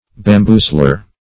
Meaning of bamboozler. bamboozler synonyms, pronunciation, spelling and more from Free Dictionary.